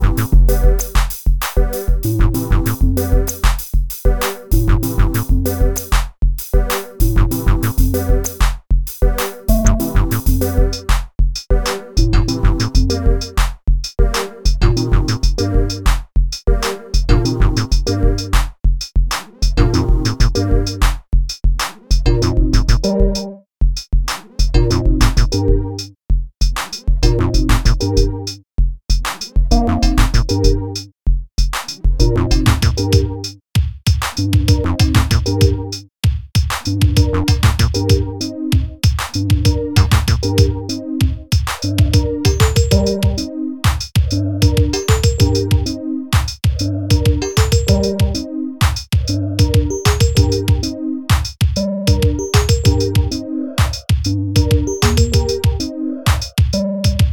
Interphase creates continually-evolving music, largely based on pattern generator algorithms that have been optimized per-instrument. The sound palette comes from a library of hand-selected and normalized audio samples, organized by instrument type: kick, snare, hihats, precussion, sound effects, bass, synth, lead. These audio samples were pulled from a few hours of Interphase running and evolving without any user interaction.